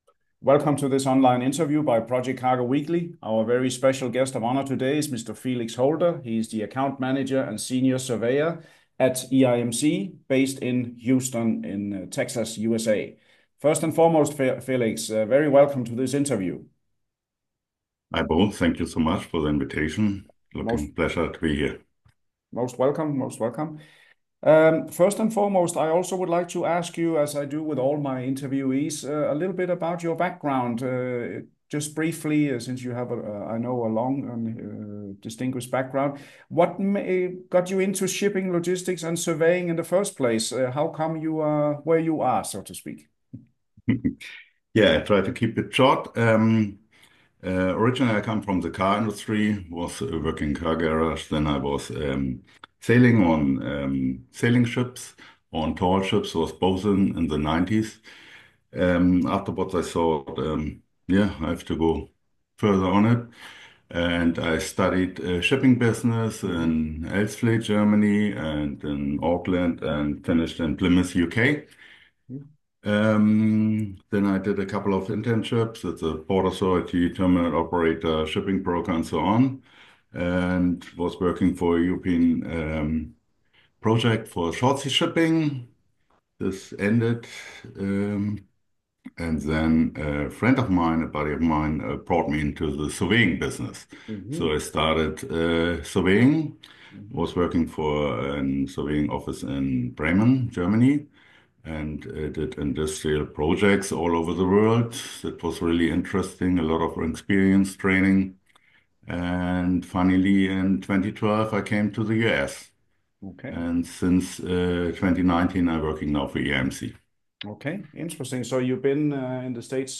Video InterviewE.I.M.C.